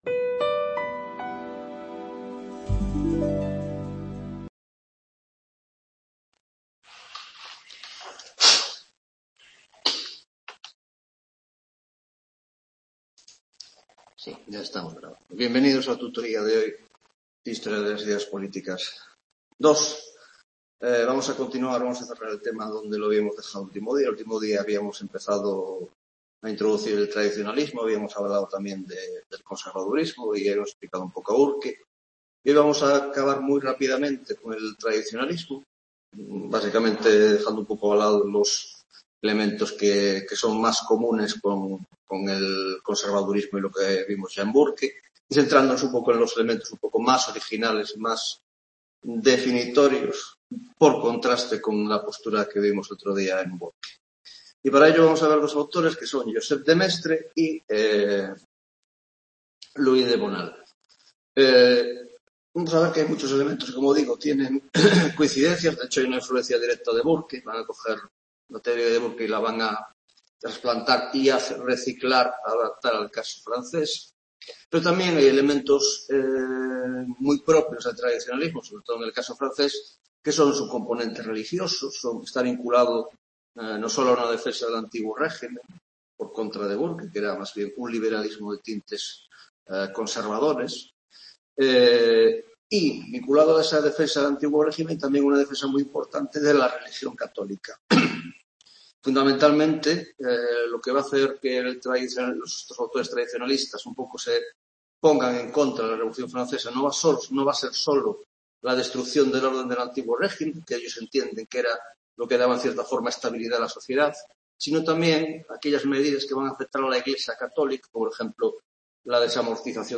9ª Tutoria de Historia de las Ideas Políticas II (Grado de Ciéncias Políticas )